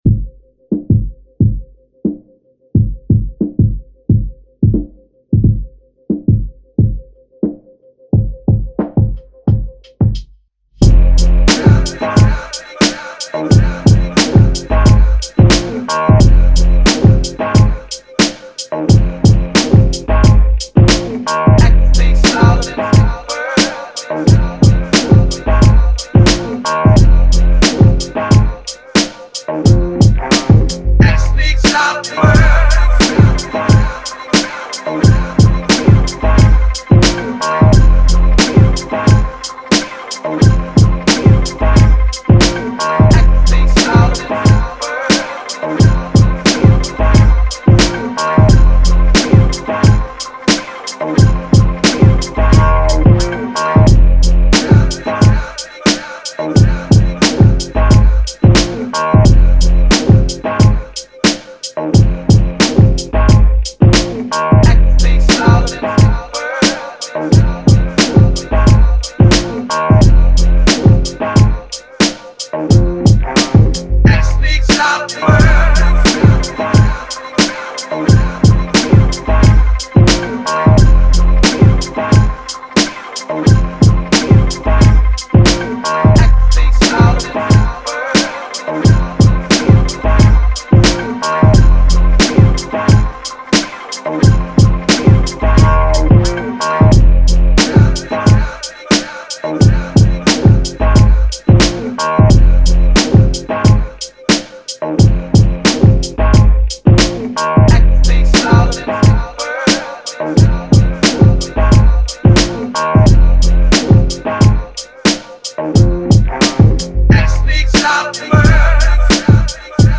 I did another quick one, probably needs an acapella again, It’s still a bit plain.
I just wanted to make something a little bit more funkier than my other one